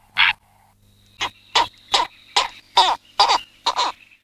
Héron pourpré
Ardea purpurea